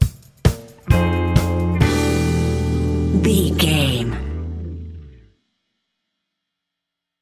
Ionian/Major
E♭
house
electro dance
synths
techno
trance